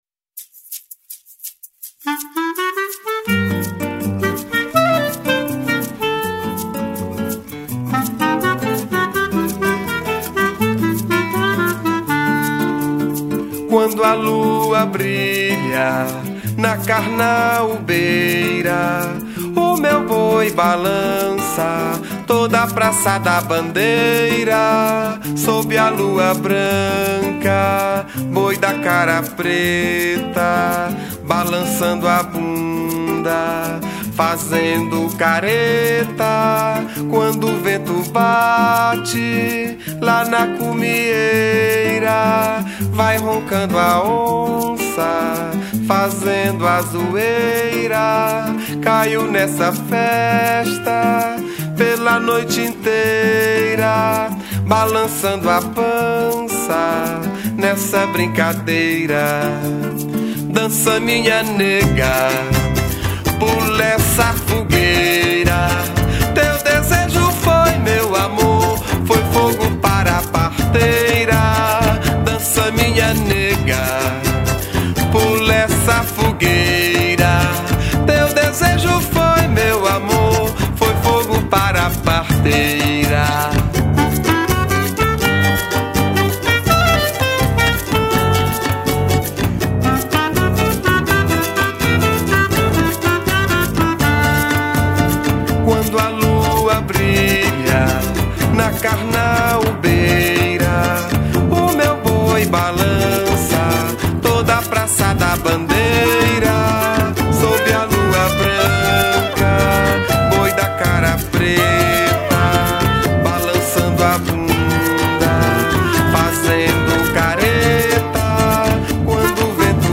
02:59:00   Boi Bumbá